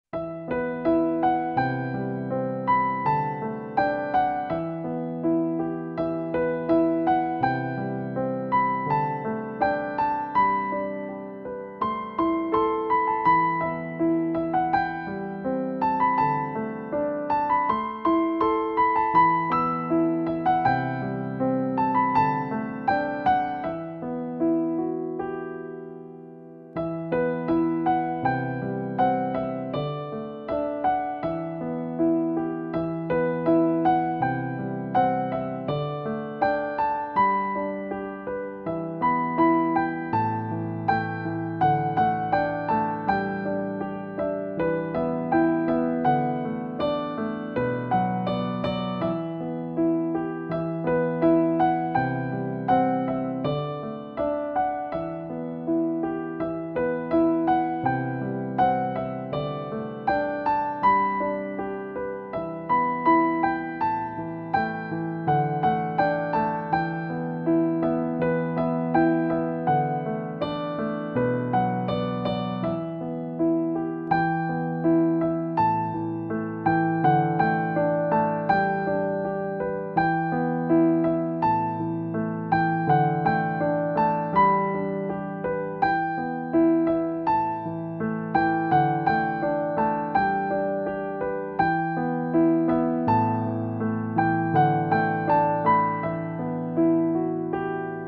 Giai điệu piano khơi gợi kỷ niệm tuổi học trò mộng mơ.